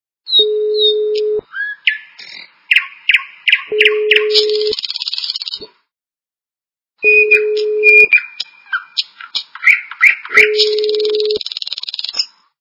Birds - Nightingale Song Звук Звуки Birds - Nightingale Song
» Звуки » Природа животные » Birds - Nightingale Song
При прослушивании Birds - Nightingale Song качество понижено и присутствуют гудки.